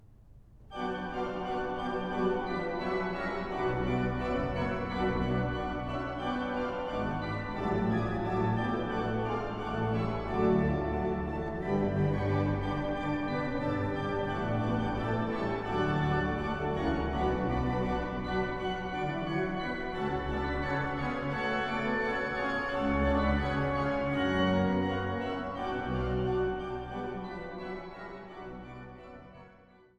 Große Sauer-Orgel der St. Johannes Kirche